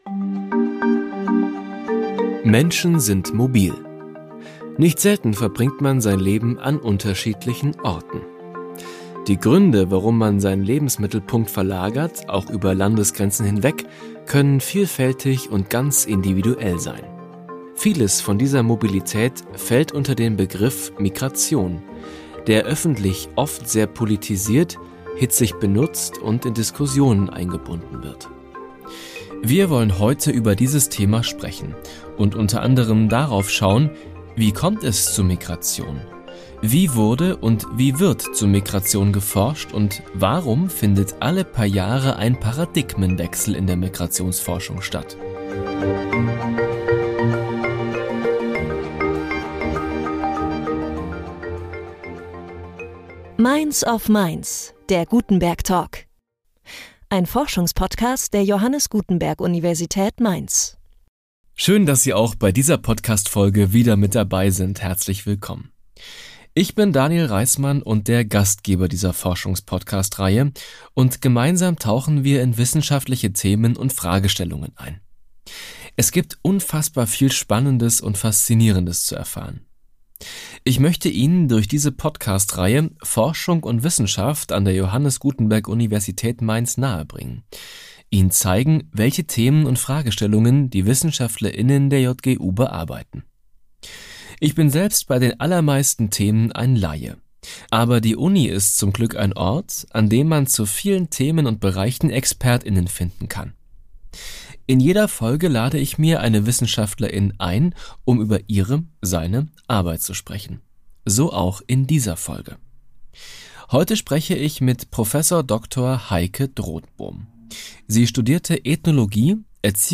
Forschungspodcast